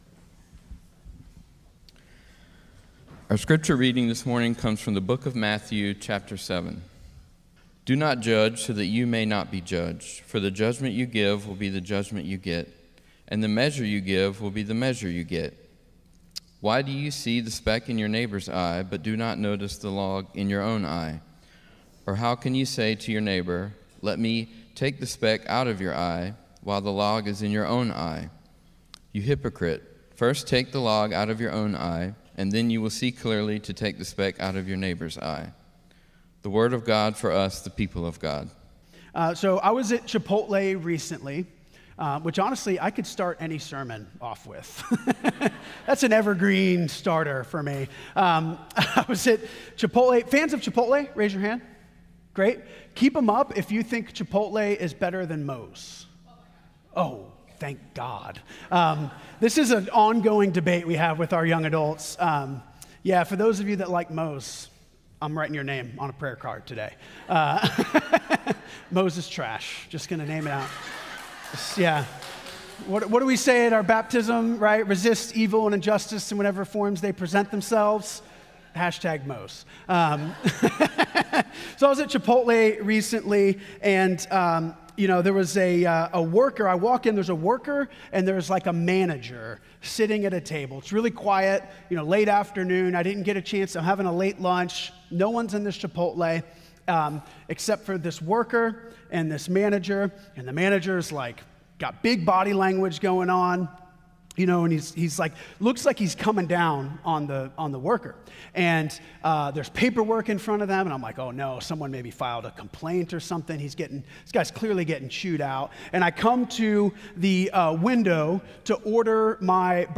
“humbled” Sermon Series, Week 2